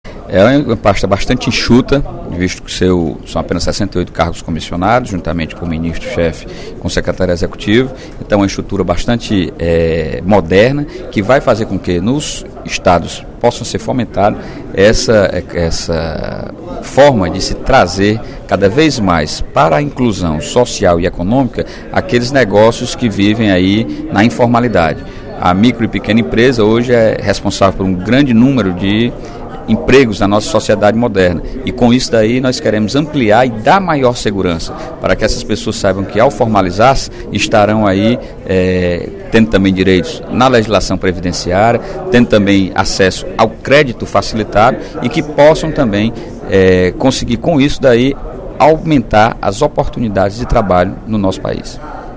O deputado Sérgio Aguiar (PSB) destacou, durante pronunciamento no primeiro expediente da Assembleia Legislativa desta quarta-feira (03/04), a criação da Secretaria da Micro e Pequena Empresa, com status de ministério.